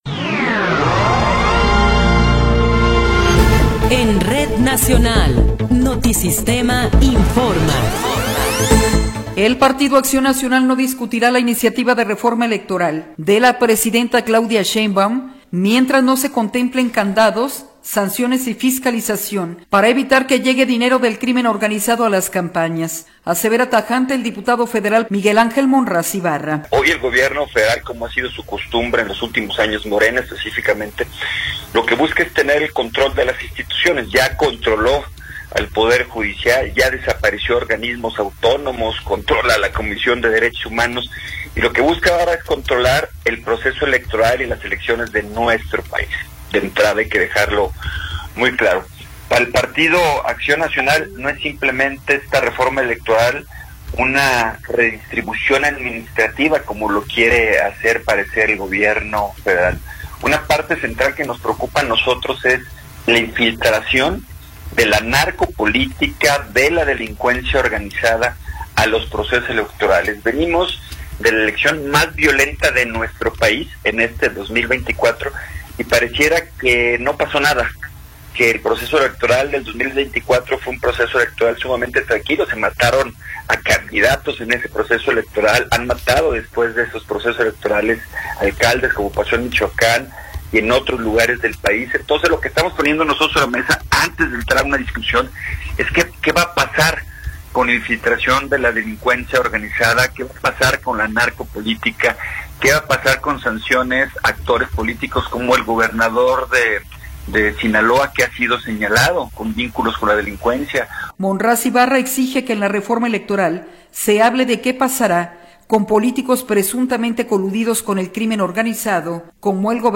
Noticiero 21 hrs. – 8 de Marzo de 2026